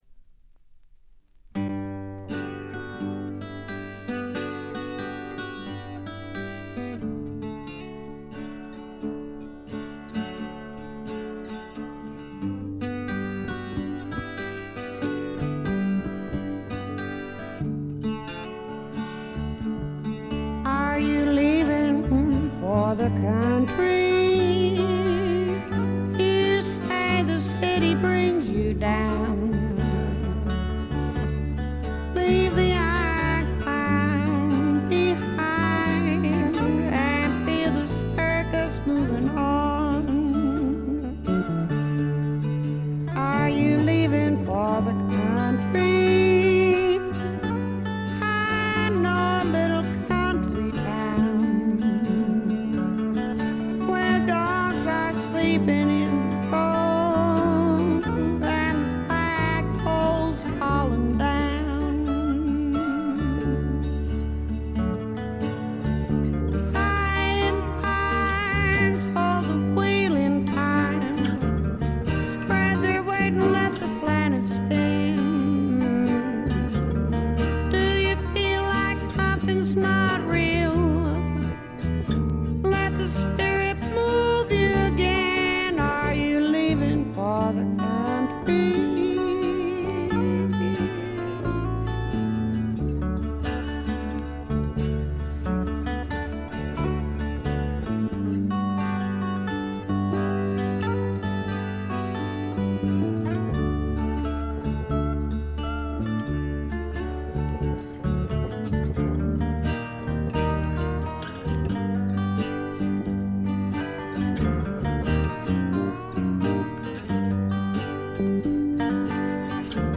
静音部での微細なチリプチが少しだけ。
試聴曲は現品からの取り込み音源です。
Vocals, Banjo, 12 String Guitar